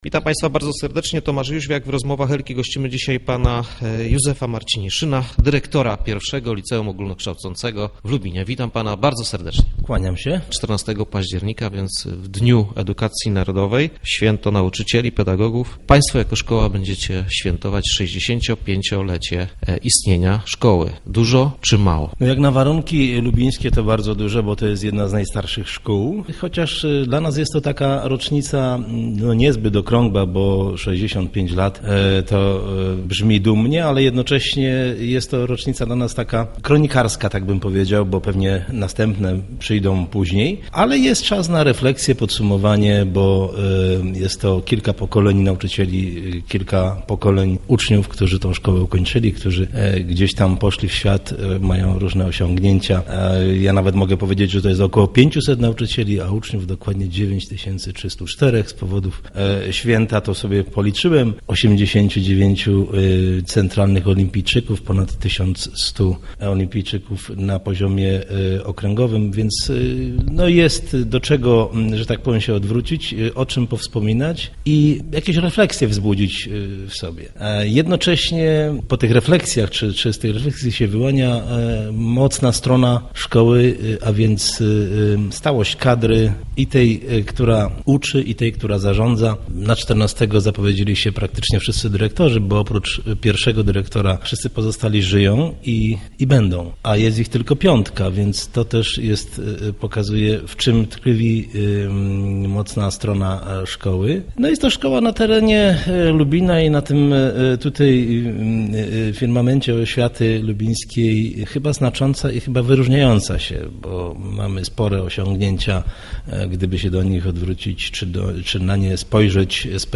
Start arrow Rozmowy Elki